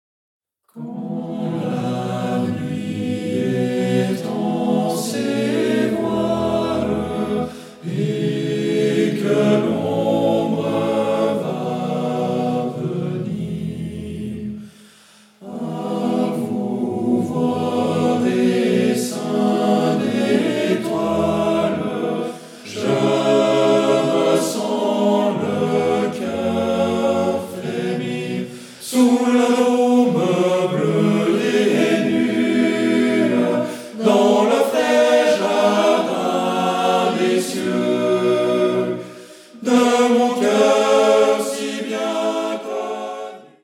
Octuor d’hommes